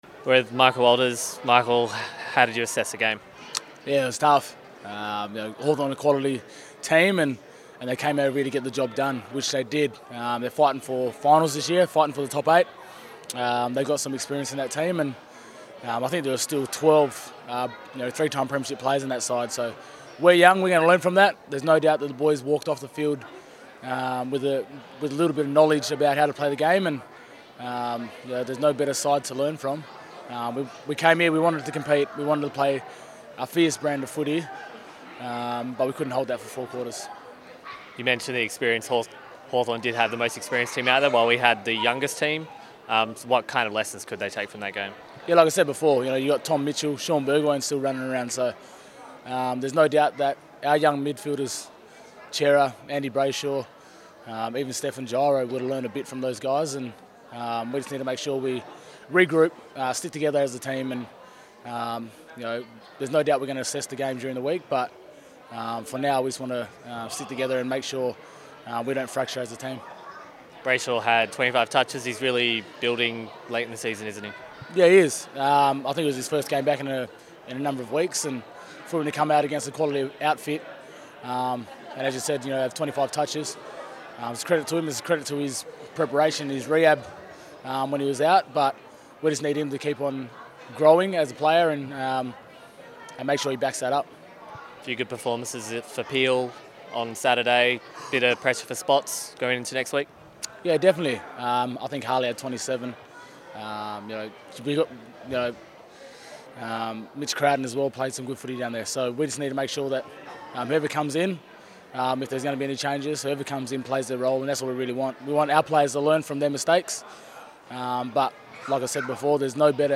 Michael Walters chats to DockerTV after round 19 against Hawthorn.